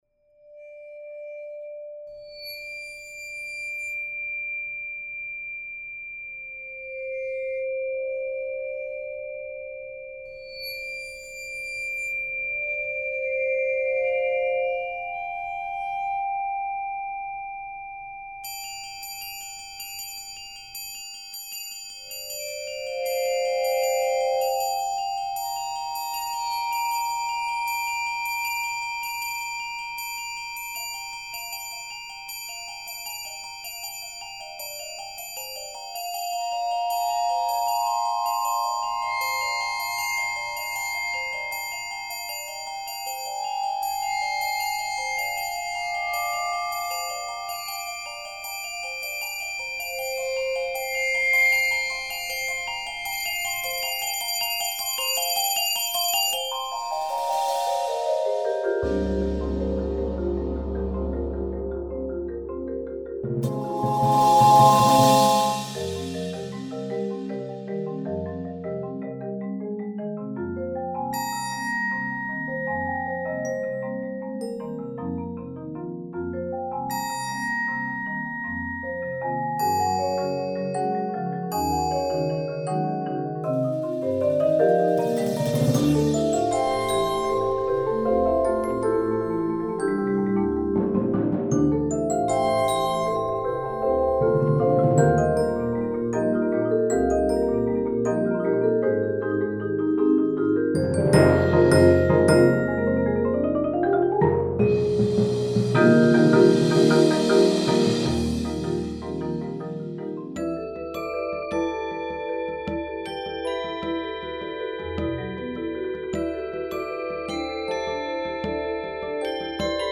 Genre: Percussion Ensemble
# of Players: 11
Bells/Xylophone
Vibraphone 1
Marimba 1 (5-octave)
Marimba 3 (5-octave), Rain Stick
Timpani (4)